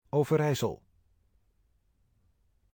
Overijssel (Dutch pronunciation: [ˌoːvərˈɛisəl]
Nl-Overijssel.oga.mp3